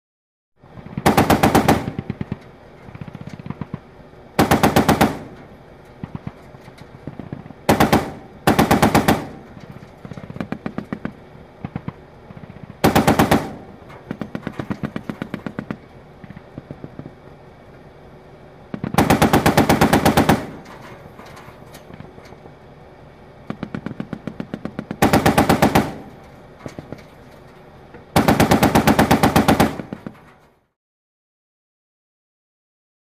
Armored Vehicle: In Combat ( Int. ); Interior Perspective Of Armored Vehicle In Combat. Automatic Shots Are Fired From The Armored Vehicle, With Distant Shots Fired In Response. Medium Perspective. Gunshots.